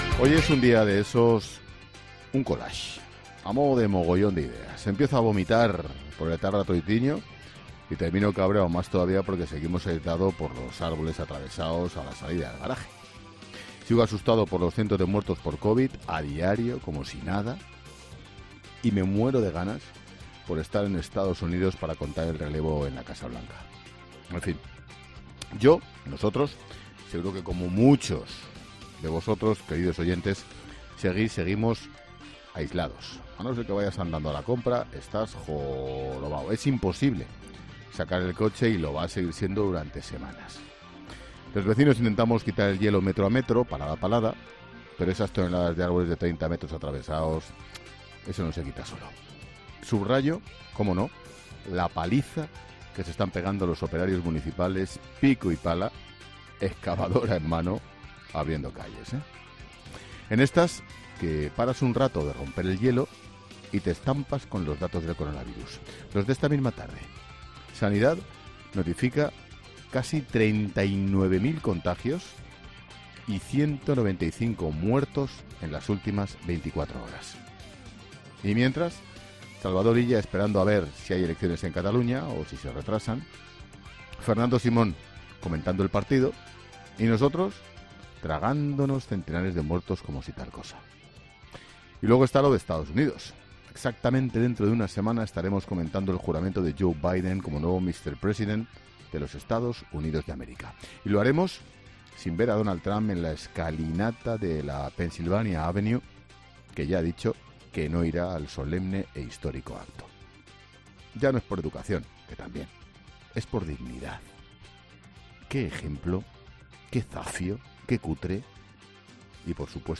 Monólogo de Expósito
El director de 'La Linterna', Ángel Expósito, analiza en su monólogo las principales claves de la actualidad de este miércoles